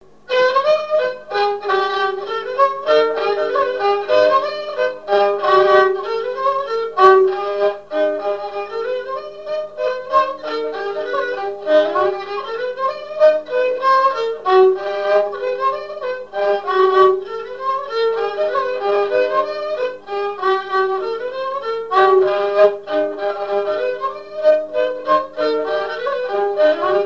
Mémoires et Patrimoines vivants - RaddO est une base de données d'archives iconographiques et sonores.
danse : mazurka
Pièce musicale inédite